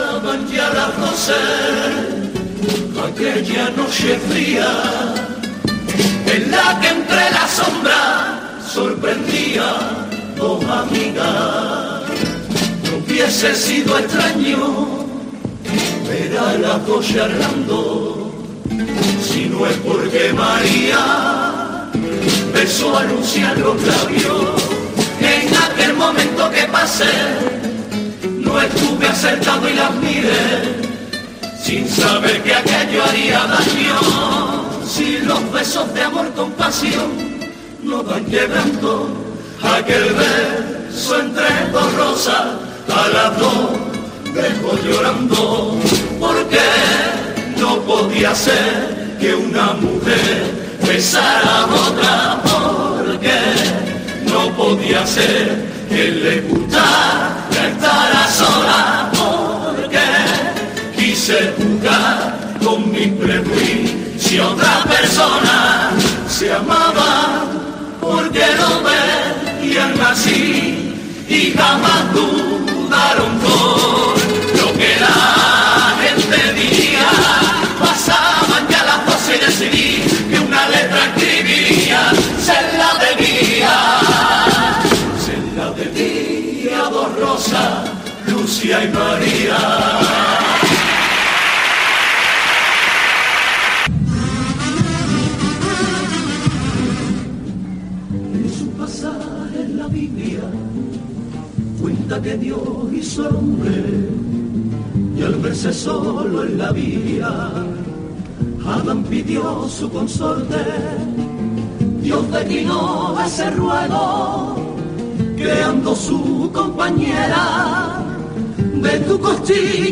5 de sus mejores pasodobles